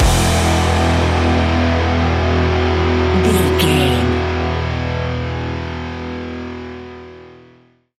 Ionian/Major
hard rock
guitars
heavy metal
scary rock
instrumentals